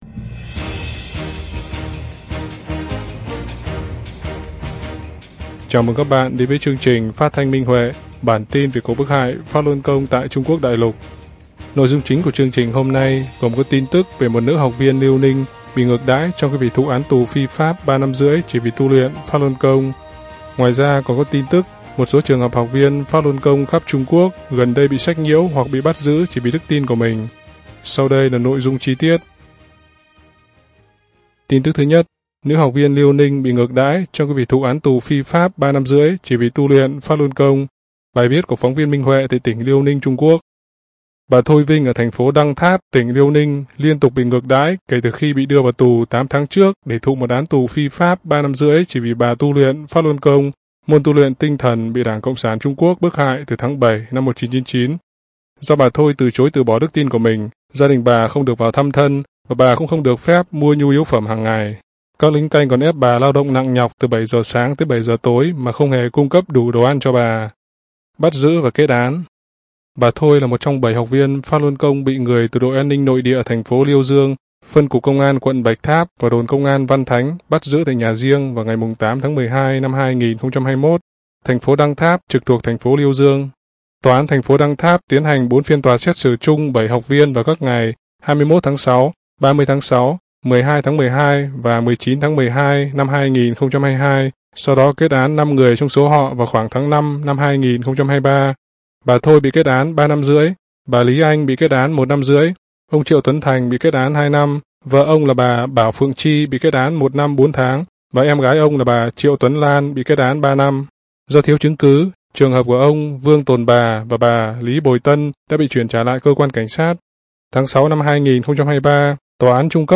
Chương trình phát thanh số 97: Tin tức Pháp Luân Đại Pháp tại Đại Lục – Ngày 25/04/2024